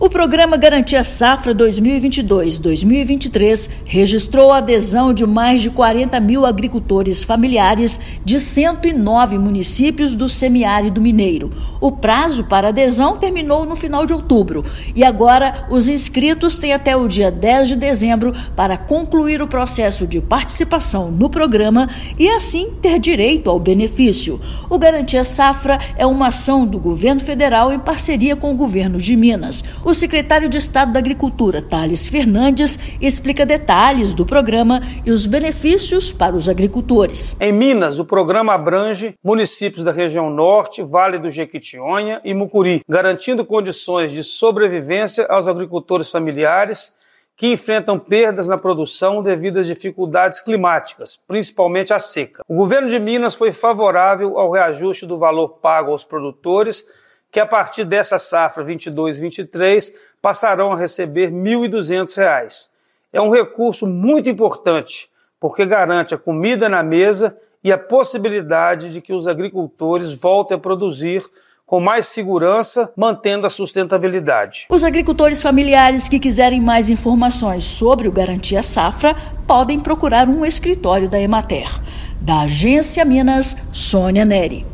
[RÁDIO] Garantia Safra 22/23 tem adesão de 41.557 agricultores familiares em Minas